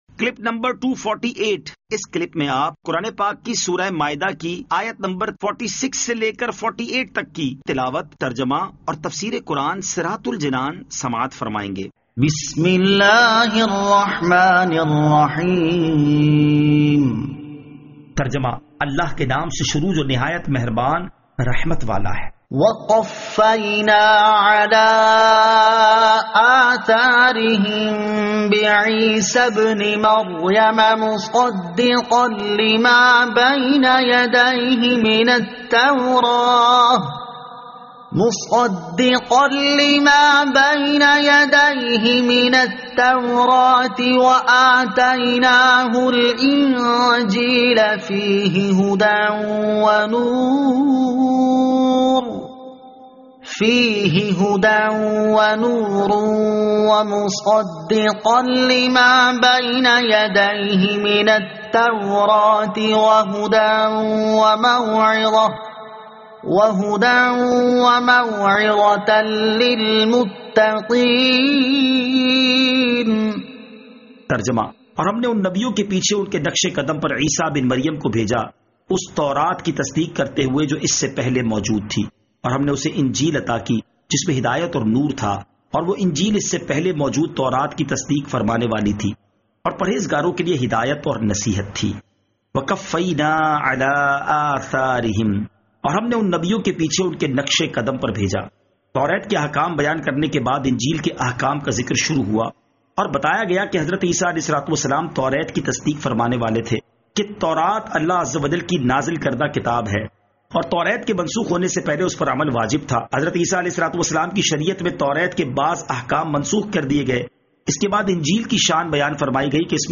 Surah Al-Maidah Ayat 46 To 48 Tilawat , Tarjama , Tafseer